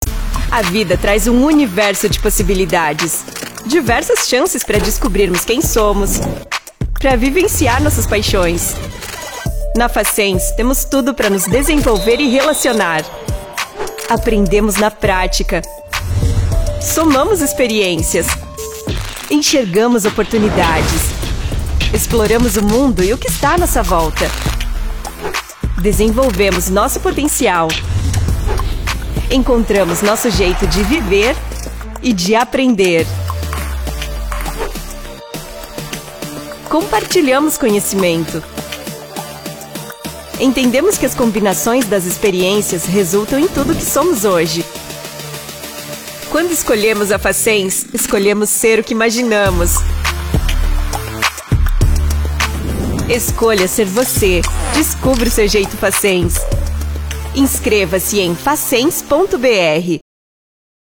locutora brasileira, com voz jovem adulta, apta para locuções em diversos estilos, versatilidade e qualidade de entrega.
Sprechprobe: Sonstiges (Muttersprache):
I am always looking to deliver the best quality, without leaving the quality and punctuality of delivery, I can speak with a neutral accent and also a Gaucho accent from the south of Brazil.